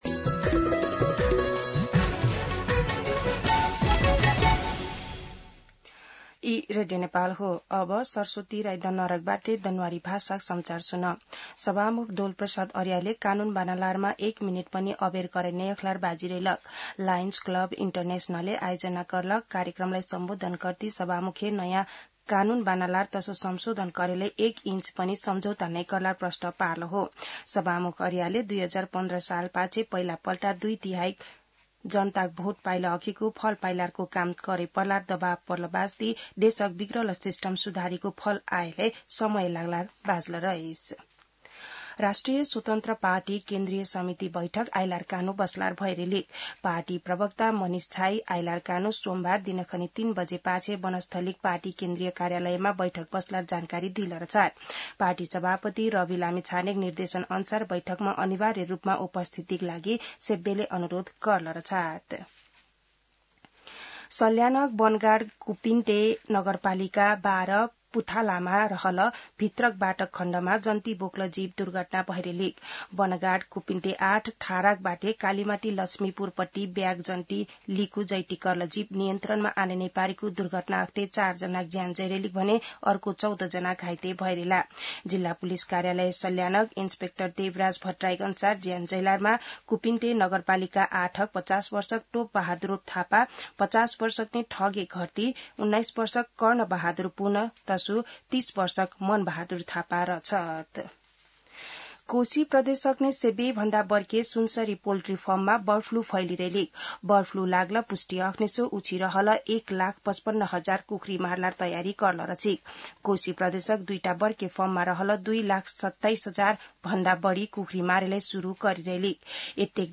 दनुवार भाषामा समाचार : ६ वैशाख , २०८३
Danuwar-News-06.mp3